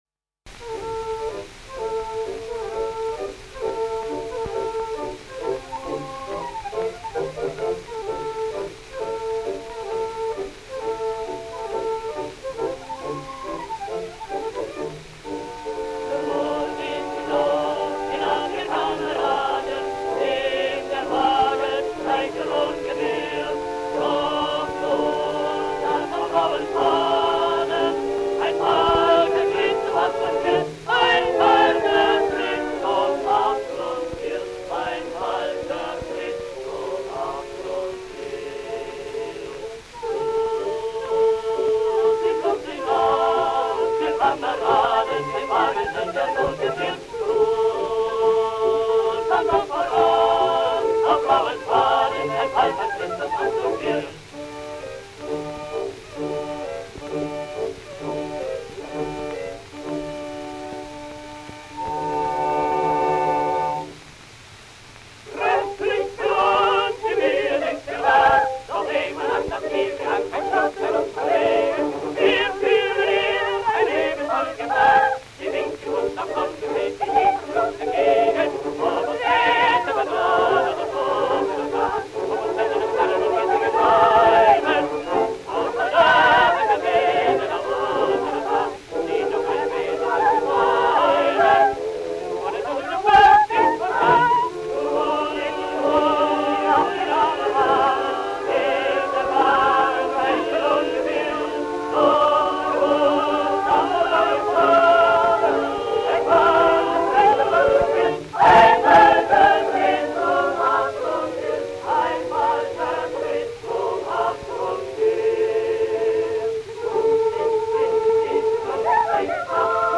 Hofop., mit Orchesterbegl.
• Oper
• Schellackplatte